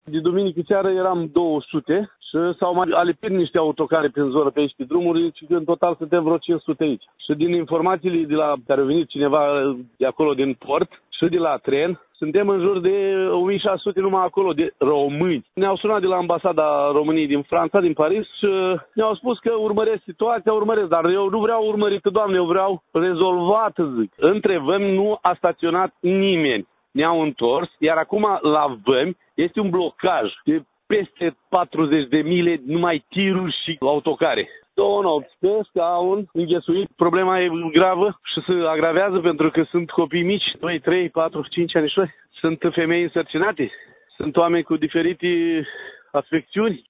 Între timp, au rămas în autocare unde și-au petrecut și nopțile, ne-a declarat unul dintre călători, un bărbat din Hârlău: